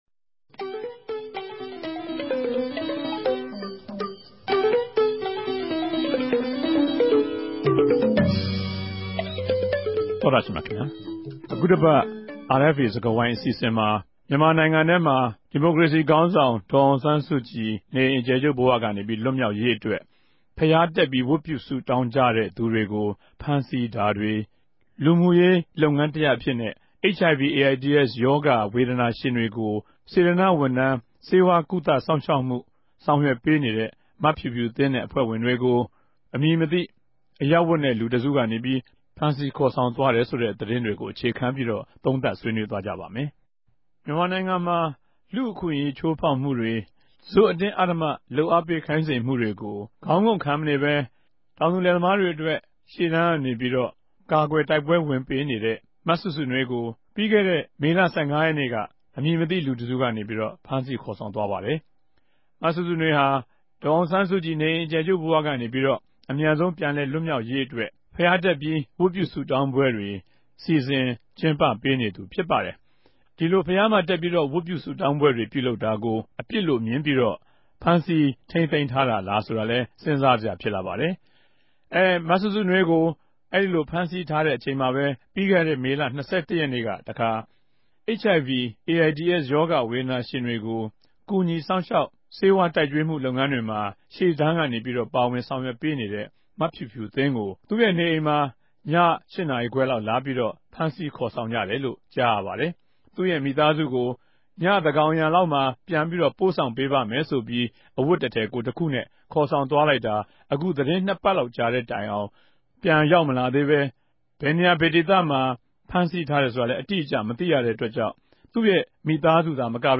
RFA ႟ုံးခဵြပ် စတူဒီယိုကနေ တယ်လီဖုန်းနဲႛ ဆက်သြယ်္ဘပီး၊ ဆြေးေိံြးထားပၝတယ်၊၊